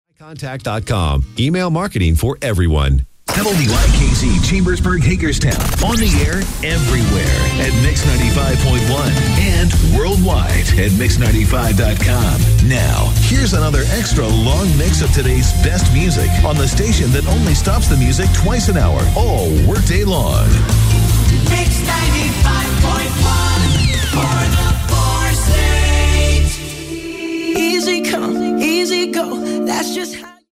WIKZ Top of the Hour Audio: